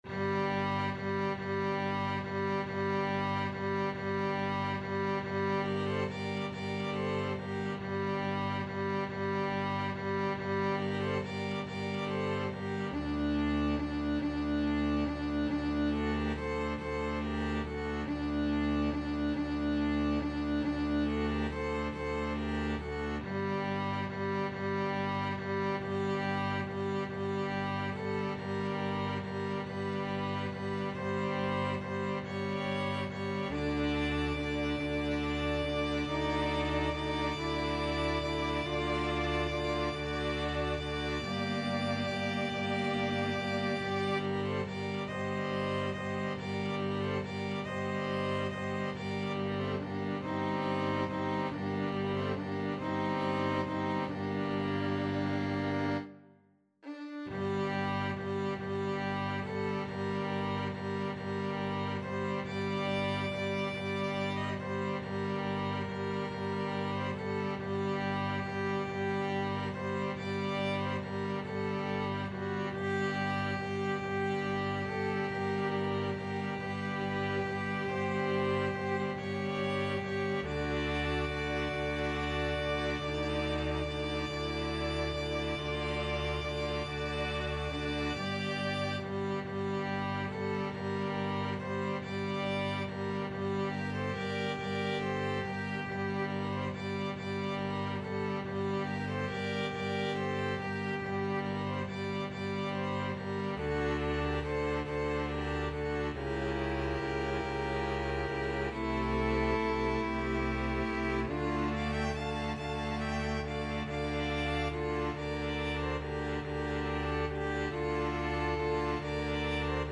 Free Sheet music for String Ensemble
Violin 1Violin 2ViolaCelloDouble Bass
Bb major (Sounding Pitch) (View more Bb major Music for String Ensemble )
~ =70 Andante
12/8 (View more 12/8 Music)
Classical (View more Classical String Ensemble Music)